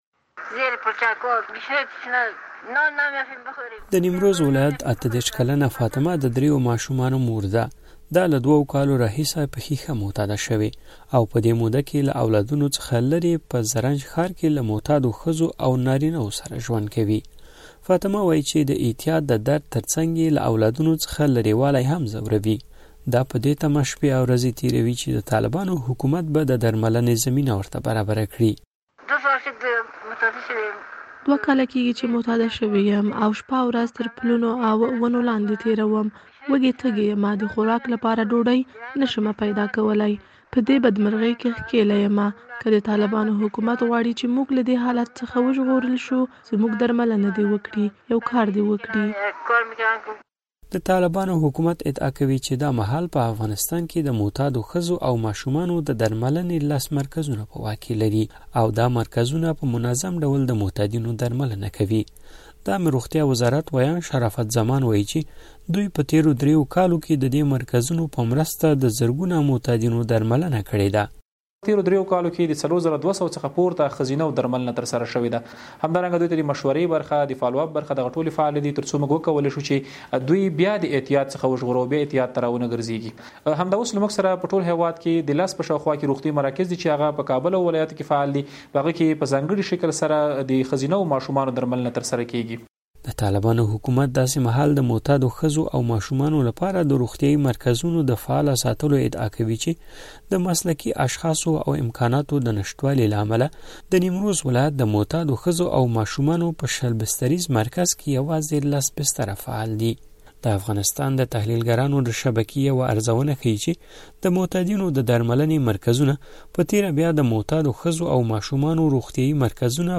د معتادو مېرمنو په اړه راپور